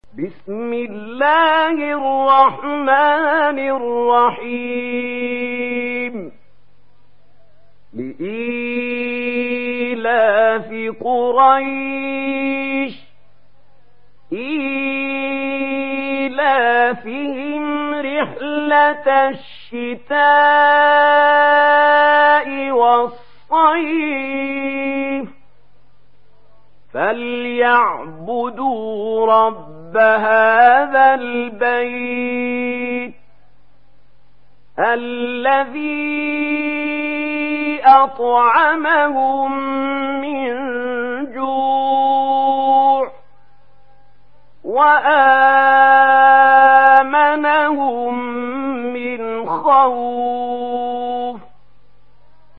Surah Quraish Download mp3 Mahmoud Khalil Al Hussary Riwayat Warsh from Nafi, Download Quran and listen mp3 full direct links